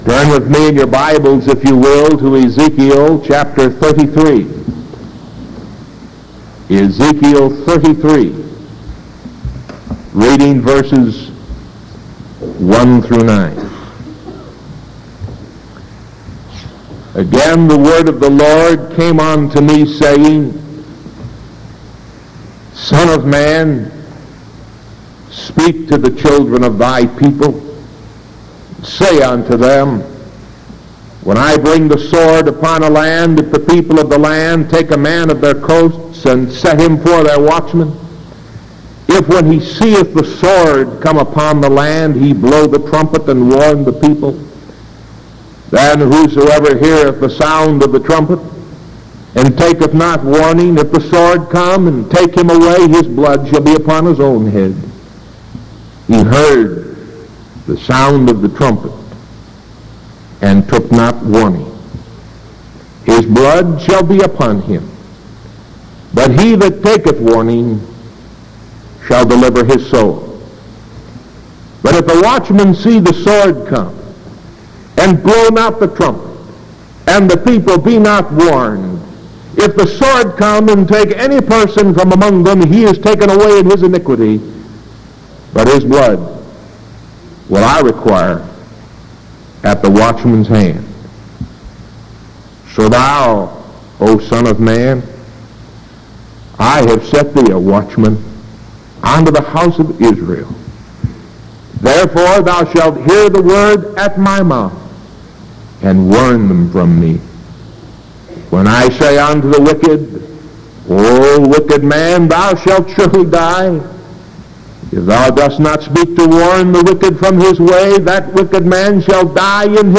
Sermon August 26th 1973 PM